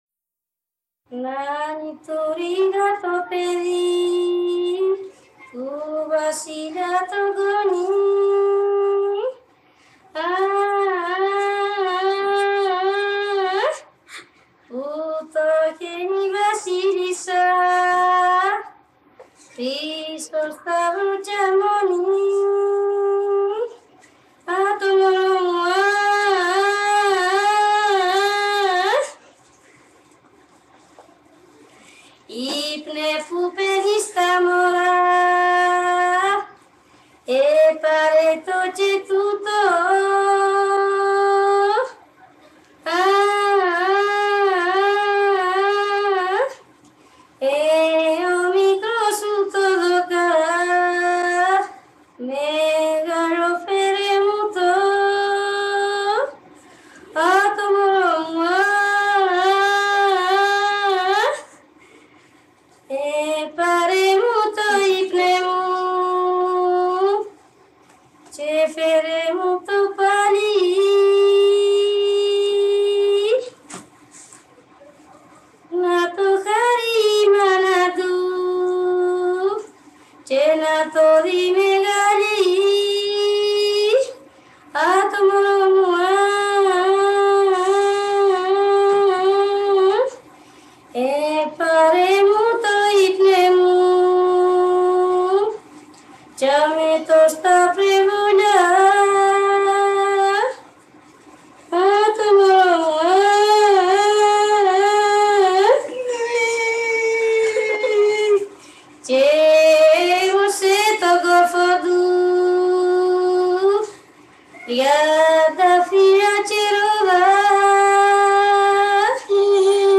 Νάξος